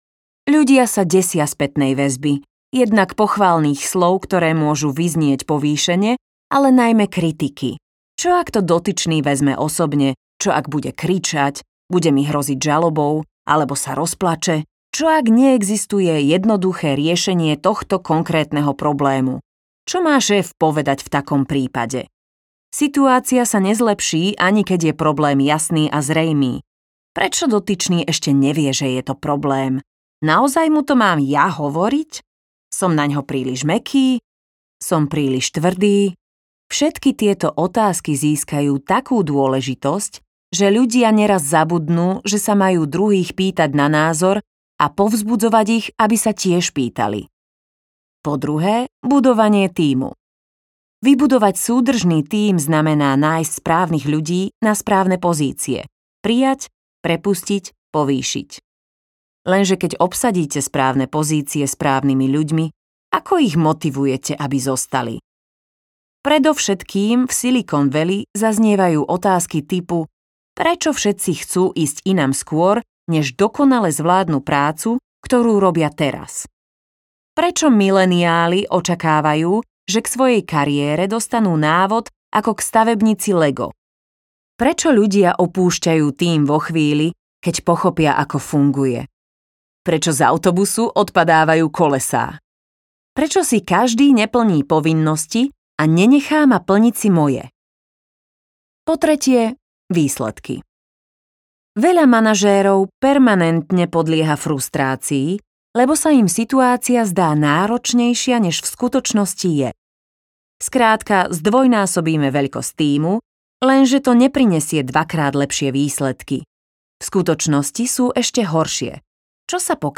Radikálna otvorenosť audiokniha
Ukázka z knihy